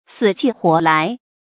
注音：ㄙㄧˇ ㄑㄩˋ ㄏㄨㄛˊ ㄌㄞˊ
死去活來的讀法